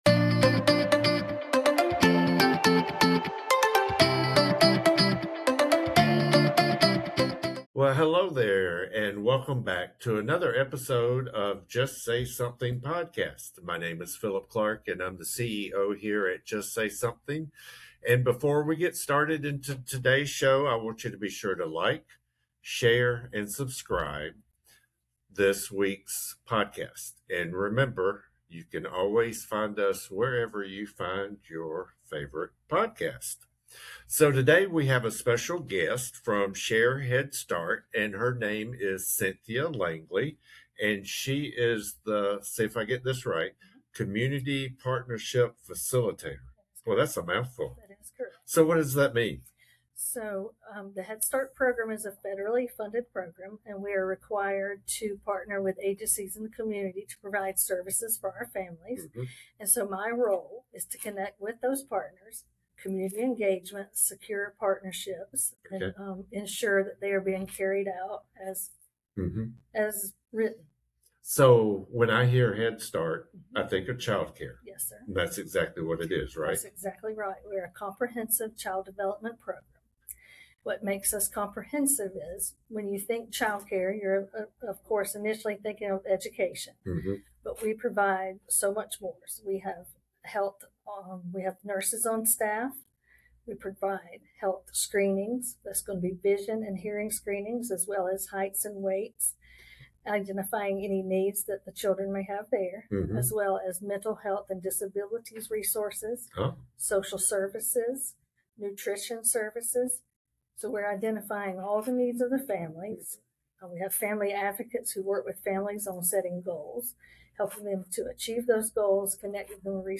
The conversation highlights the growing needs families are facing, including financial literacy and support with challenging behaviors, and how strong community partnerships help fill those gaps.